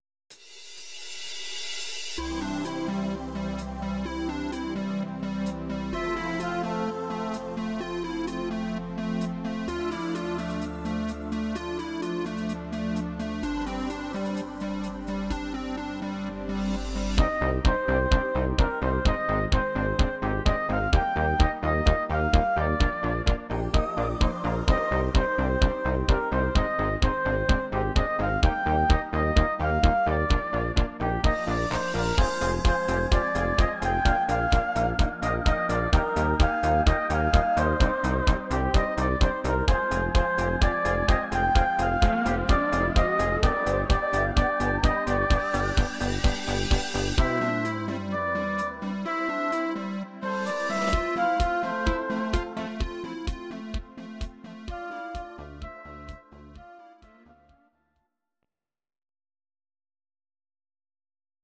Audio Recordings based on Midi-files
Pop, German, 2010s